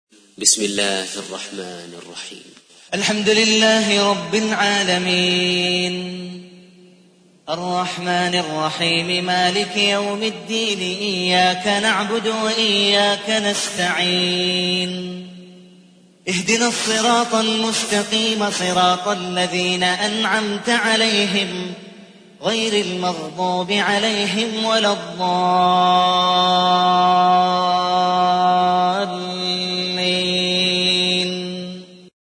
1. سورة الفاتحة / القارئ